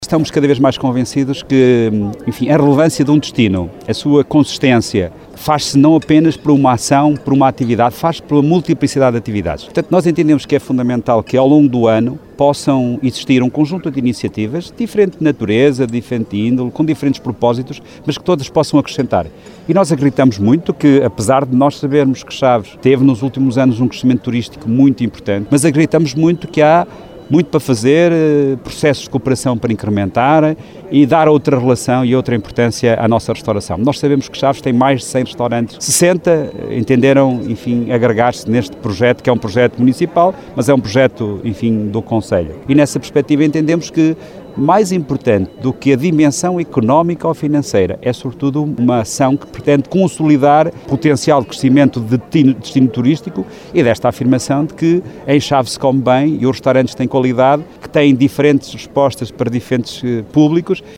Pelo segundo ano, a autarquia de Chaves promoveu este Festival na Galiza, um evento que o presidente da Câmara, Nuno Vaz considera relevante e que promove o concelho.